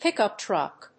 アクセントpíckup trùck